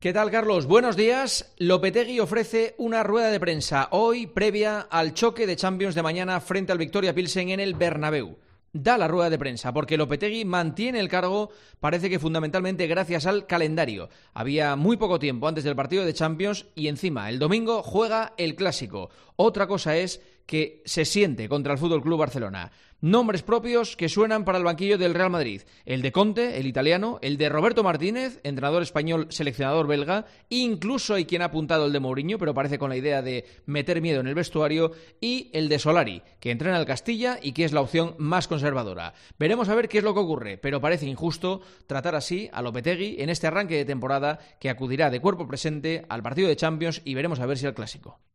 El comentario de Juanma Castaño
Escucha el comentairo del director de 'El Partidazo' de COPE en 'Herrera en COPE'